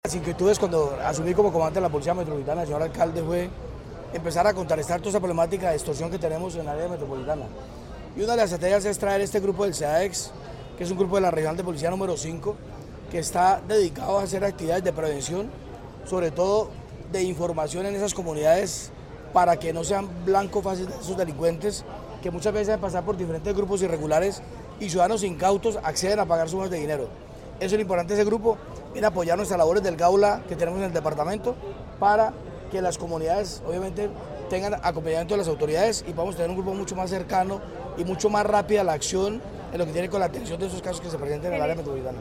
Brigadier General William Quintero Salazar, Comandante de la Policía Metropolitana de Bucaramanga